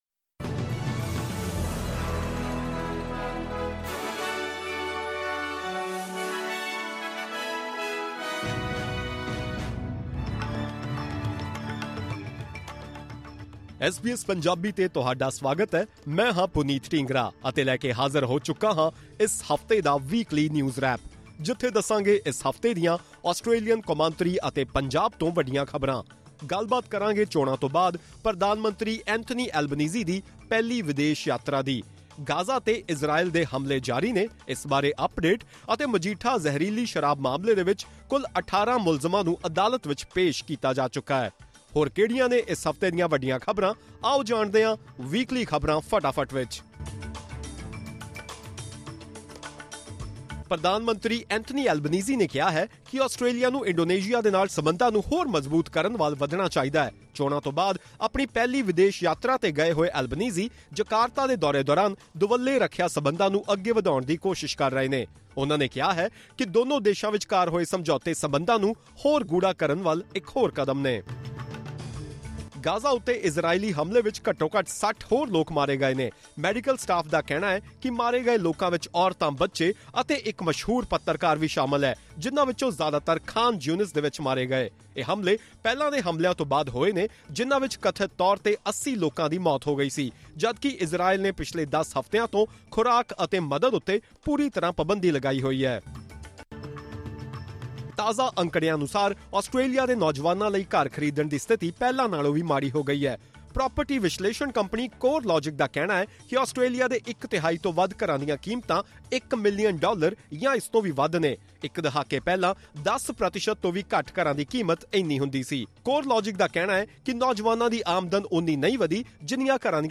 Find out in this Weekly News in Brief.